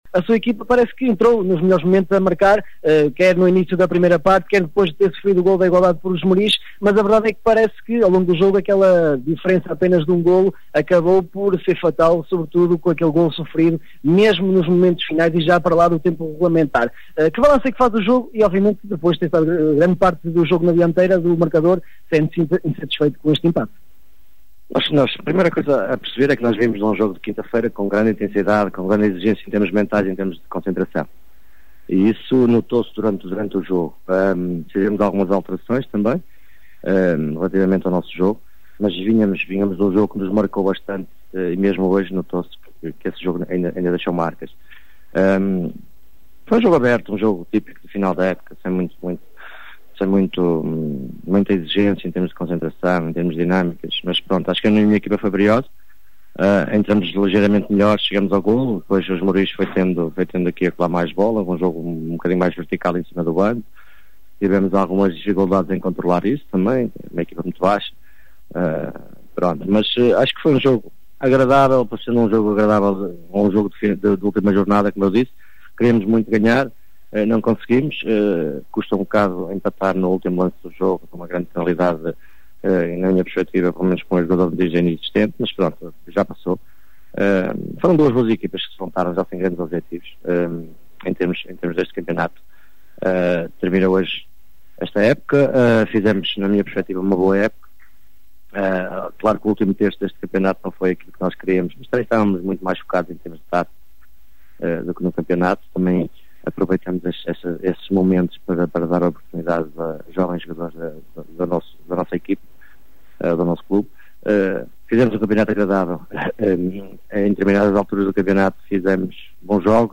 • Declarações Fiães SC